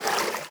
swim1.wav